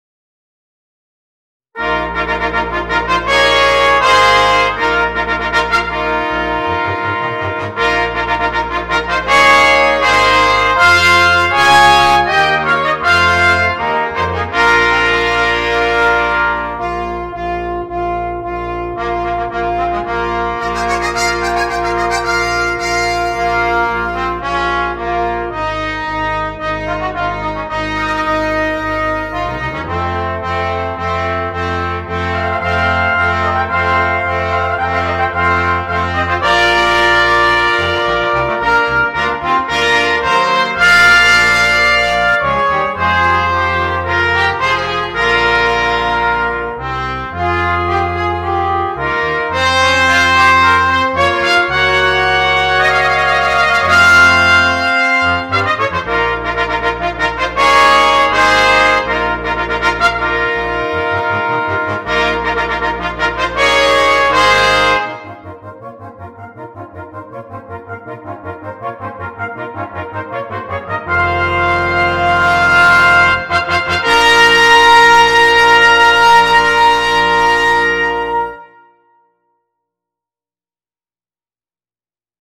Gattung: für Blechbläserquintett
Besetzung: Ensemblemusik für Blechbläser-Quintett